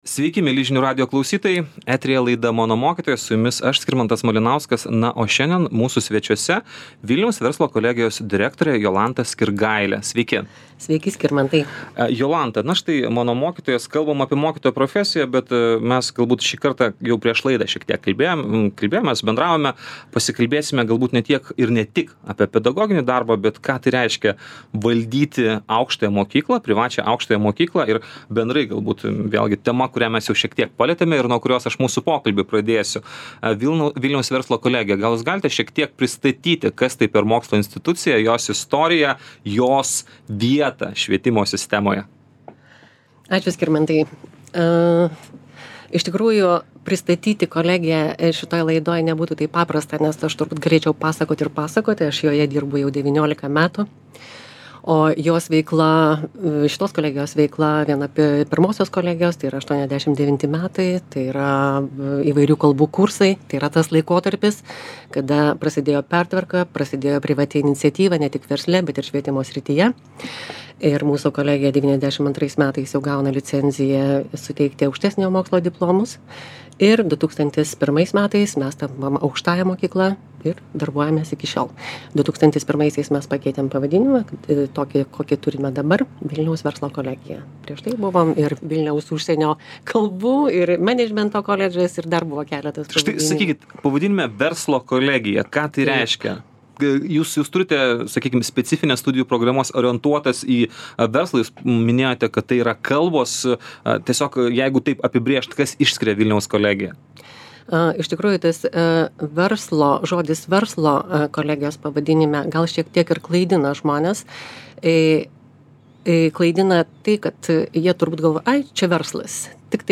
Pokalbis